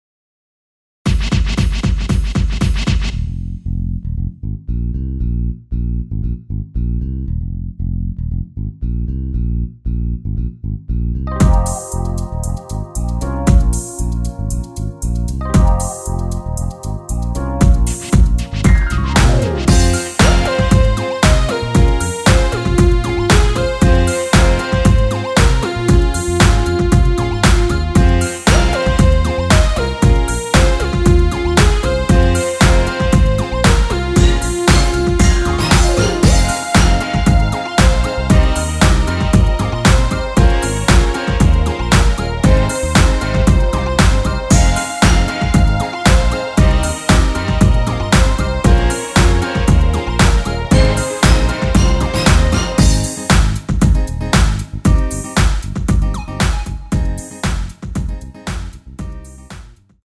Fm
◈ 곡명 옆 (-1)은 반음 내림, (+1)은 반음 올림 입니다.
앞부분30초, 뒷부분30초씩 편집해서 올려 드리고 있습니다.
중간에 음이 끈어지고 다시 나오는 이유는